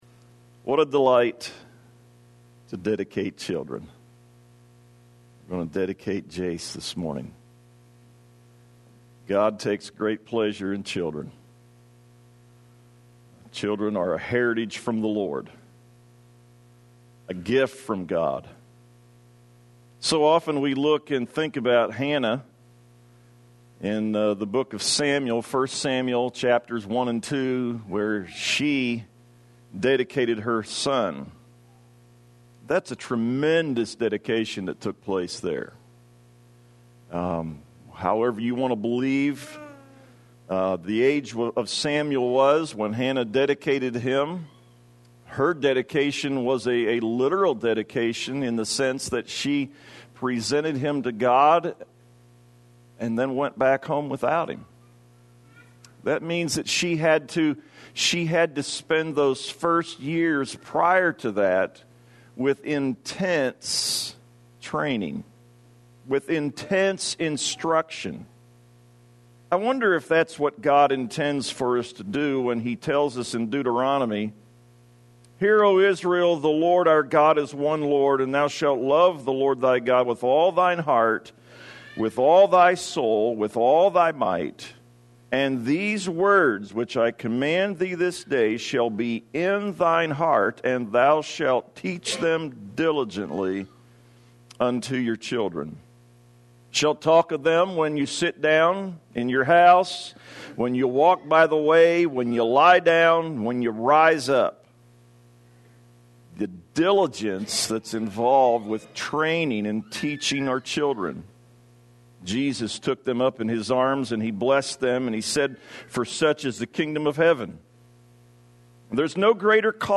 Tagged with baby dedication , child raising , child training